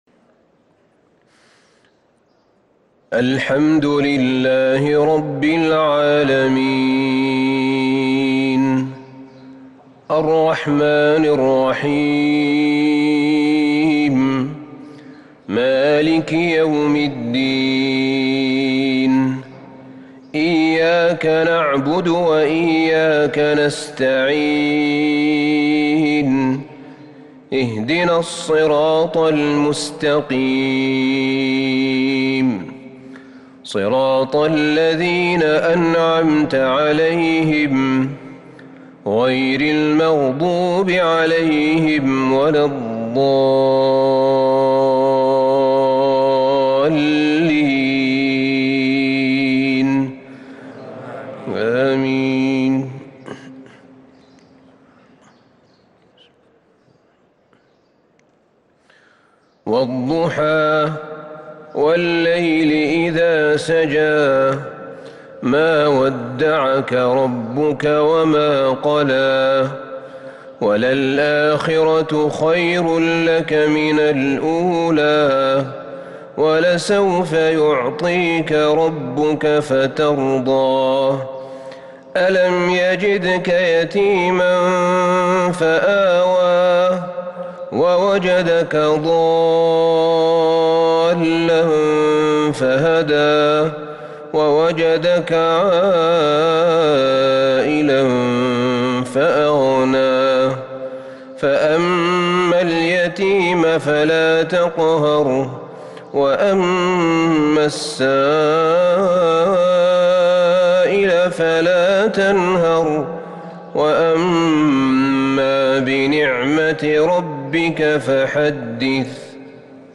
مغرب السبت 4-7-1443هـ سورتي الضحى و الشرح | Maghrib prayer from Surat Ad-Dhuhaa and Ash-Sharh 5-2-2022 > 1443 🕌 > الفروض - تلاوات الحرمين